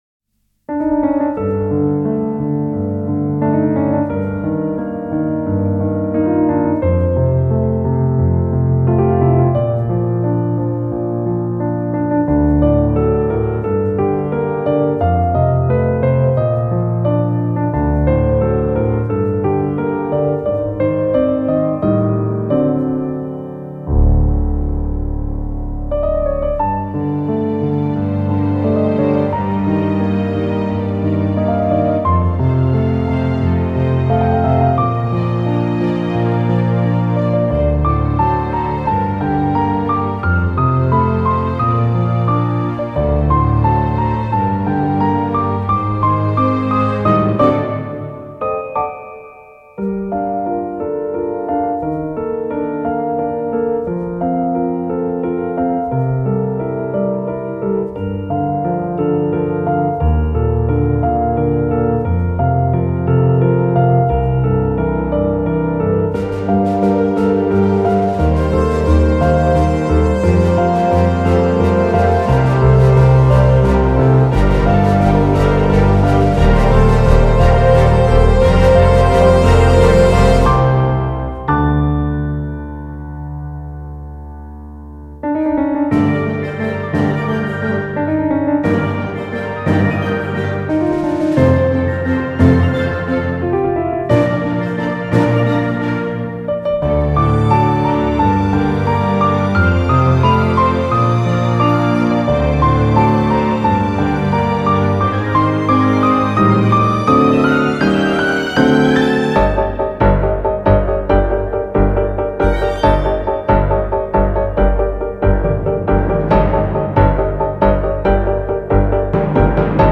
●曲調切ない映画テーマ
■楽器ピアノ、フルオーケストラ
雰囲気 感動 切ない シリアス 不安 悲しい
ジャンル アコースティック クラシック オーケストラ ピアノ